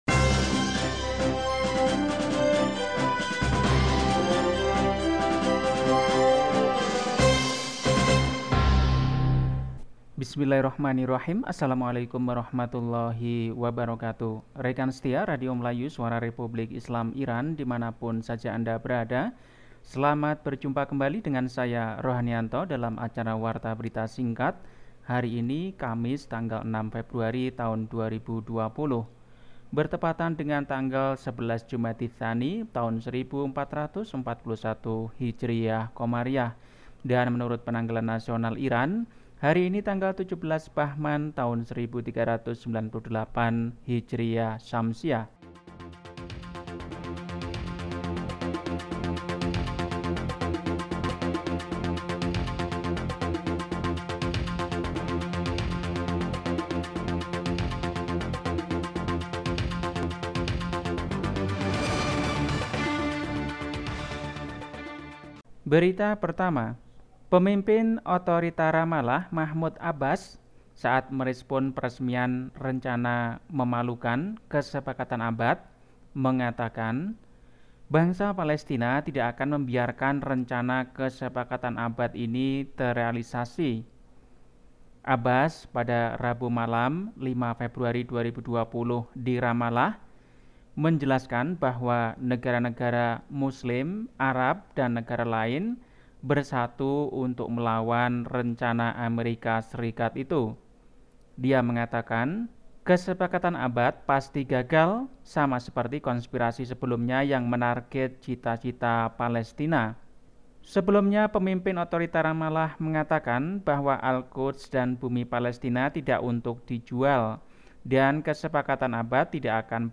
Berita 6 Februari 2020